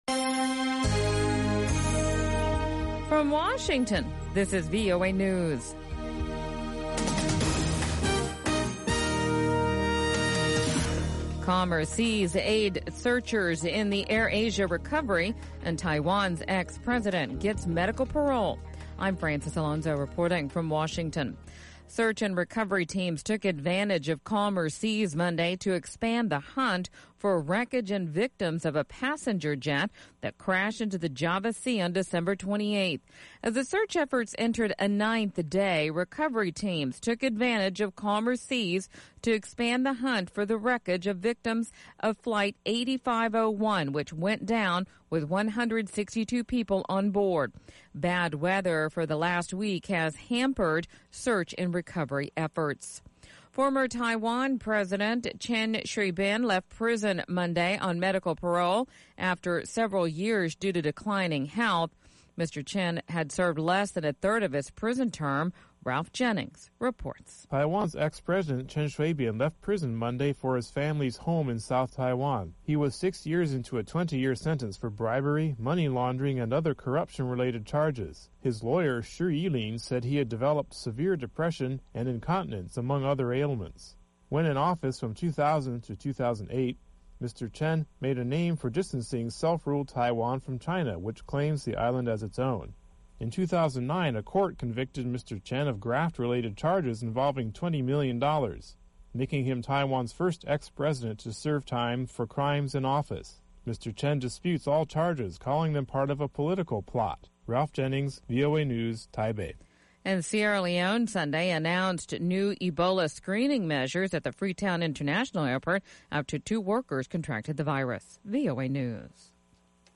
pan-African music